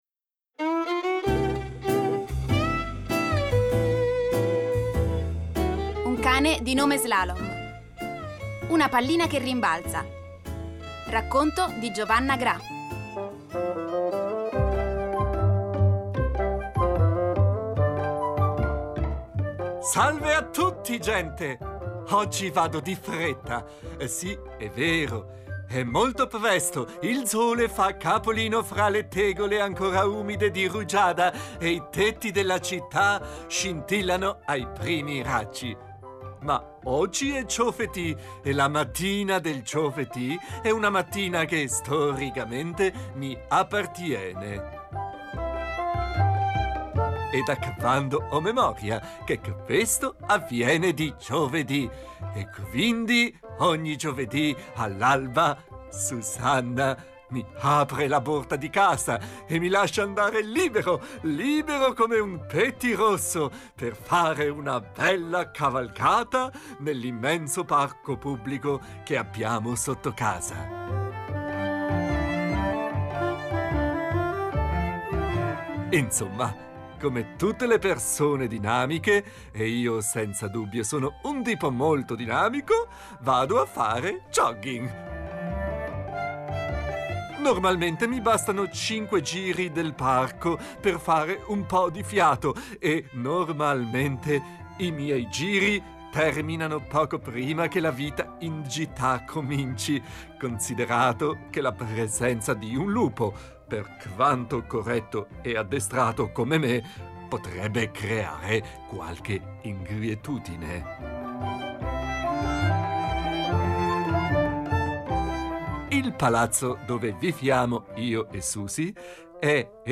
Una pallina che rimbalza [Racconto di Giovanna Gra] [ascolta l'audiodescrizione] Salve a tutti, gente!